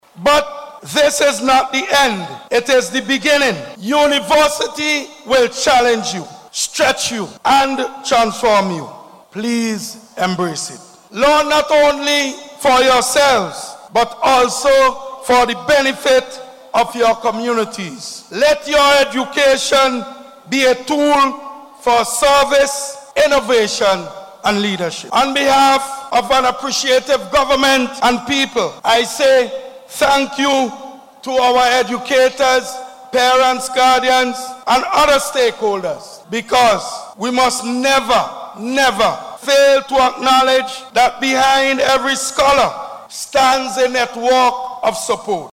Speaking at the recent Schools’ Independence Rally, he encouraged bursary and award recipients to view their studies as a foundation for serving and leading within their communities.